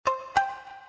zvuk_soobcsheniya.mp3